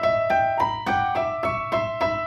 Index of /musicradar/gangster-sting-samples/105bpm Loops
GS_Piano_105-E2.wav